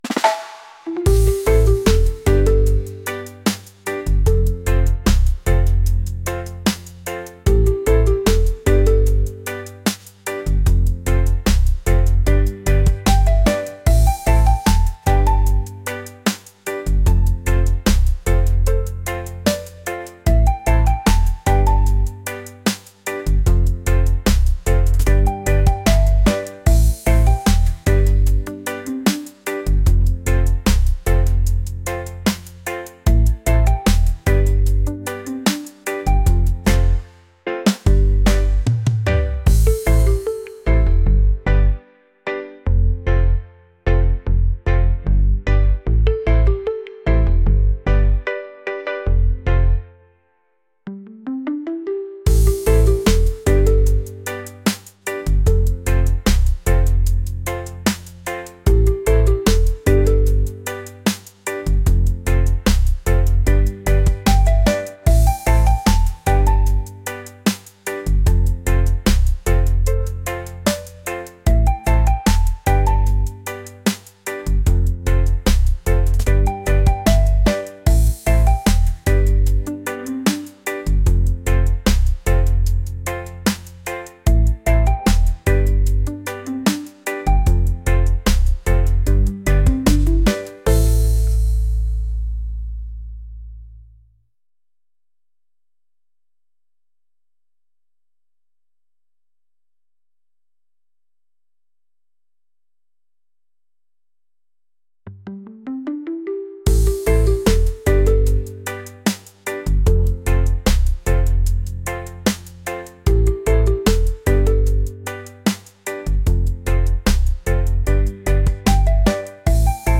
laid-back | reggae | vibes